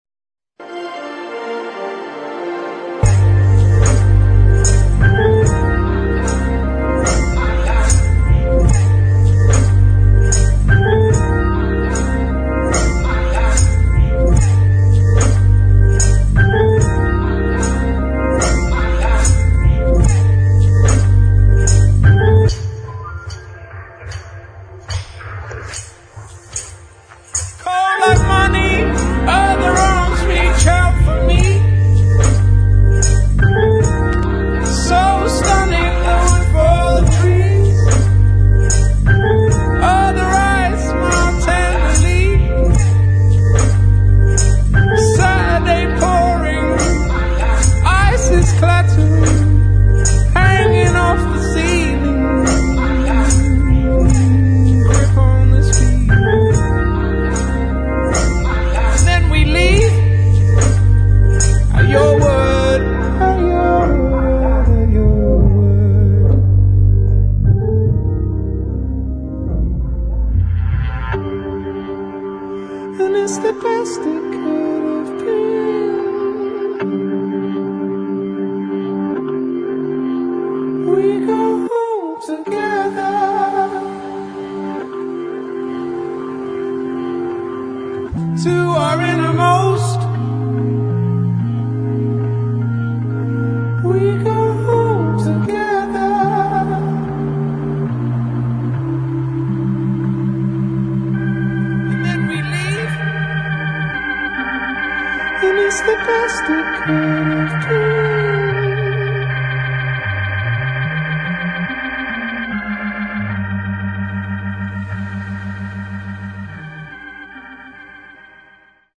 [ DOWNBEAT / INDIE ]